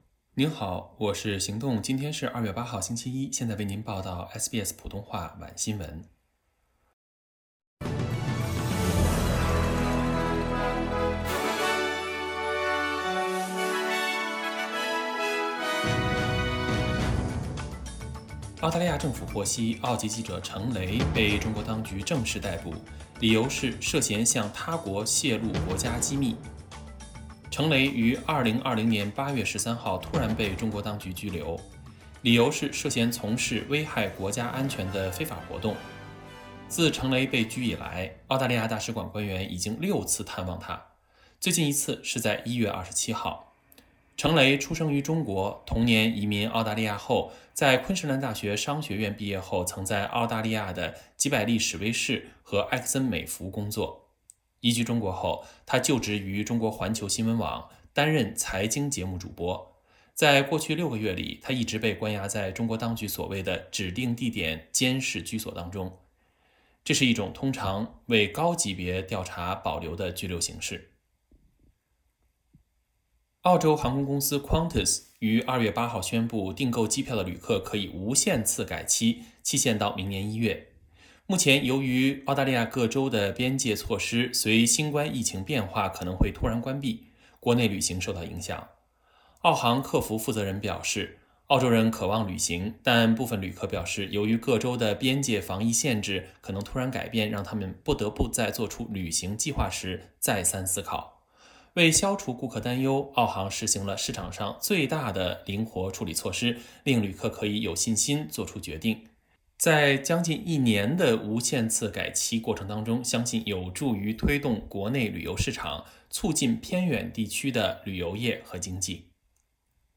SBS晚新聞（2月8日）